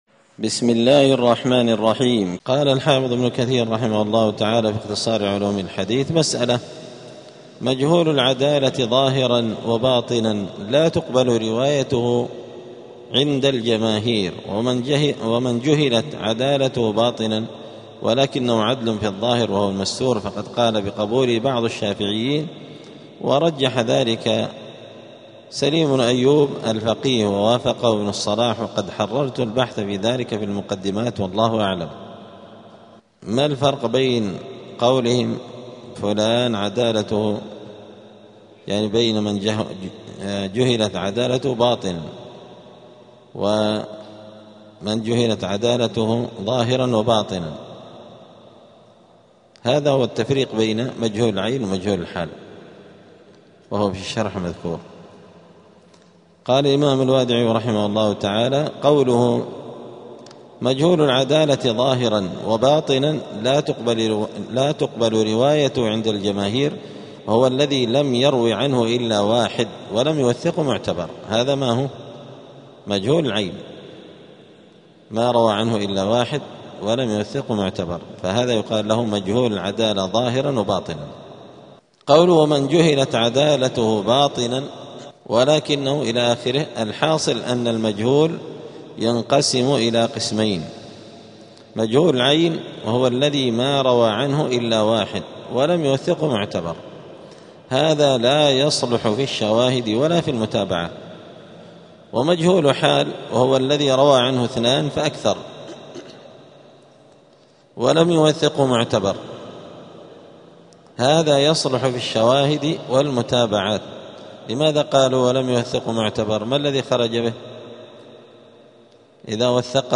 دار الحديث السلفية بمسجد الفرقان قشن المهرة اليمن
63الدرس-الثالث-والستون-من-السير-الحثيث.mp3